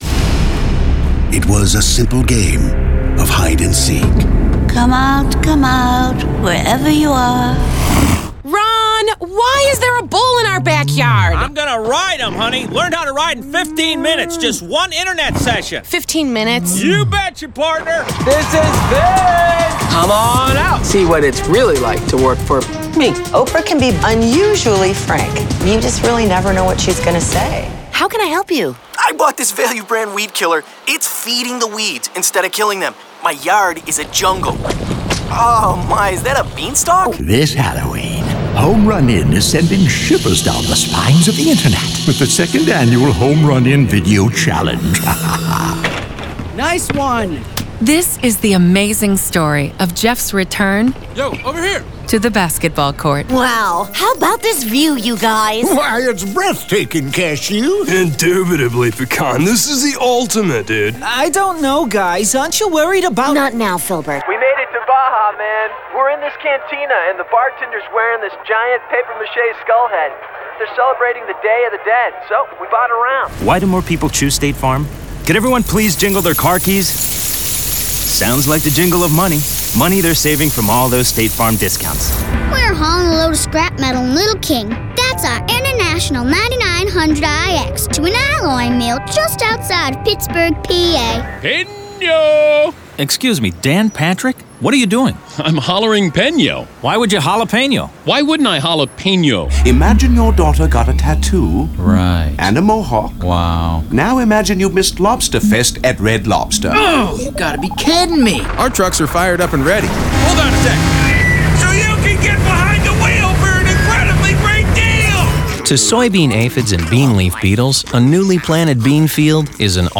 From creative writing, to creative audio landscapes and theater-of-the-mind, we’ve been there every step of the way.
BAM-Studios-Radio-Demo-Reel.mp3